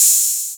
808-OpenHiHats08.wav